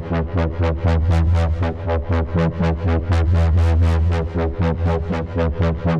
Index of /musicradar/dystopian-drone-samples/Tempo Loops/120bpm
DD_TempoDroneA_120-E.wav